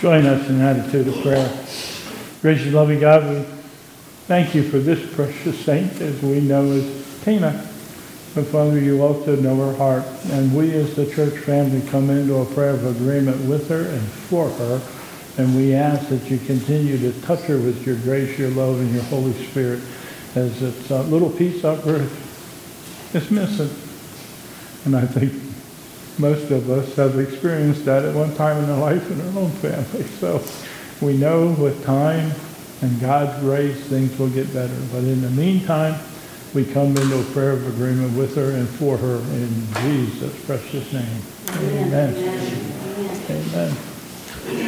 2022 Bethel Covid Time Service